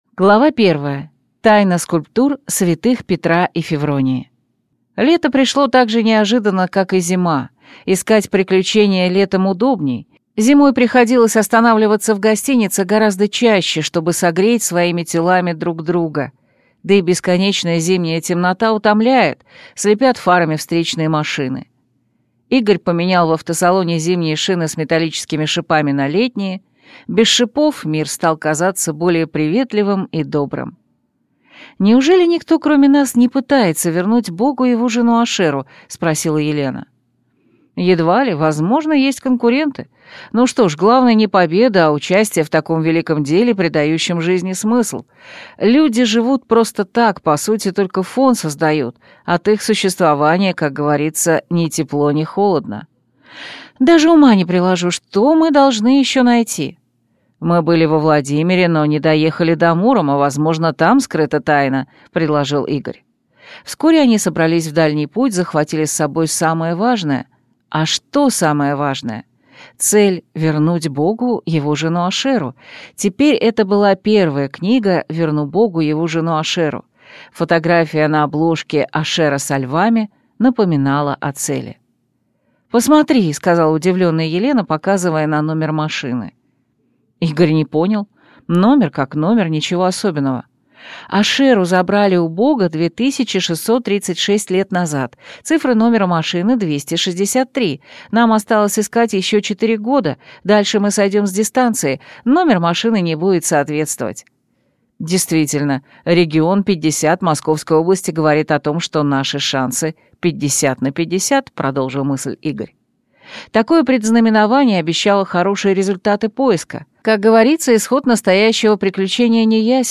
Аудиокнига Верну Богу его жену Ашеру. Книга третья | Библиотека аудиокниг